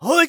xys蓄力2.wav 0:00.00 0:00.30 xys蓄力2.wav WAV · 26 KB · 單聲道 (1ch) 下载文件 本站所有音效均采用 CC0 授权 ，可免费用于商业与个人项目，无需署名。
人声采集素材